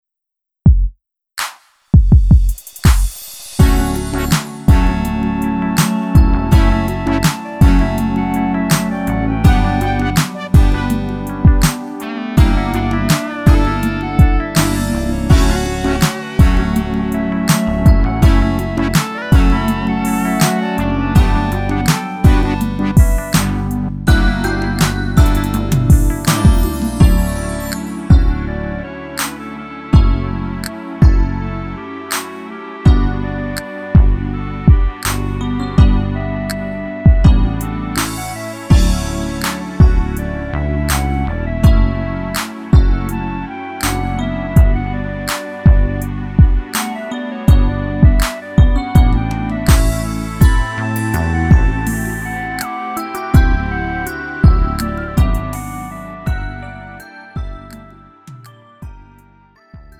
음정 원키 4:07
장르 가요 구분